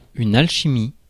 Ääntäminen
Synonyymit science hermétique philosophie hermétique Ääntäminen France: IPA: [al.ʃi.mi] Haettu sana löytyi näillä lähdekielillä: ranska Käännös Substantiivit 1. алхимия Suku: f .